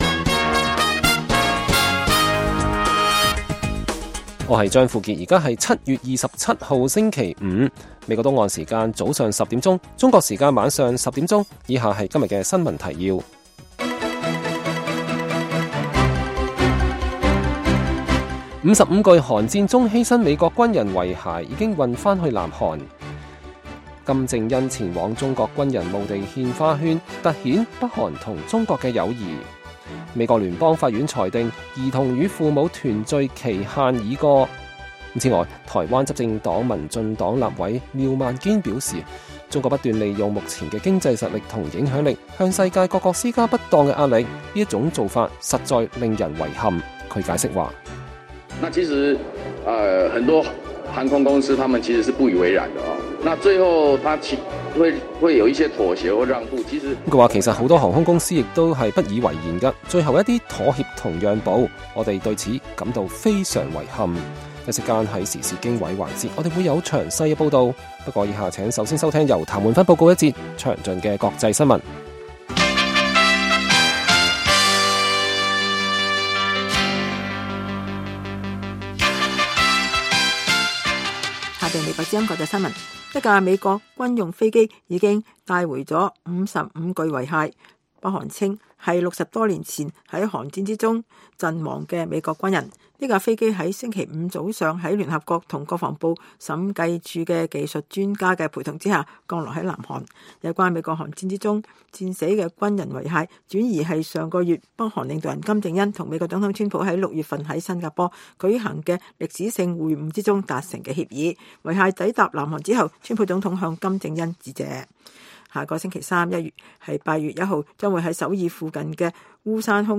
粵語新聞 晚上10-11點
北京時間每晚10－11點 (1400-1500 UTC)粵語廣播節目。內容包括國際新聞、時事經緯和社論。